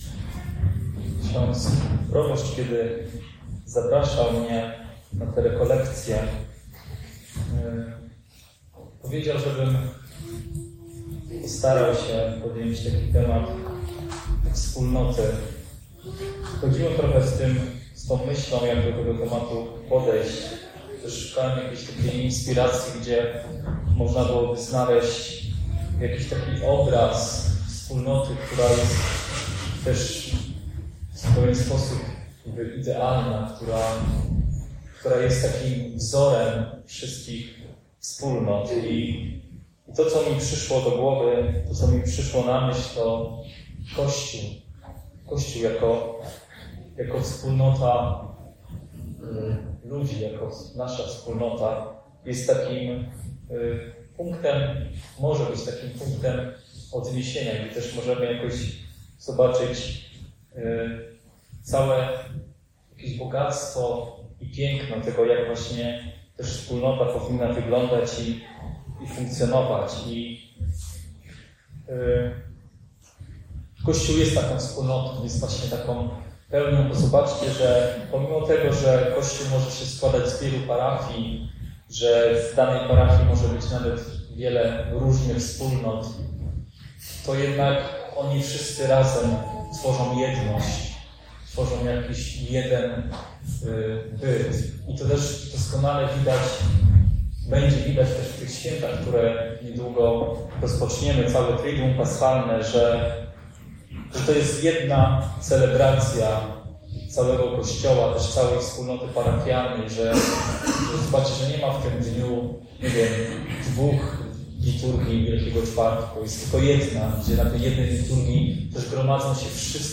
kazanie pierwsze rekolekcyjne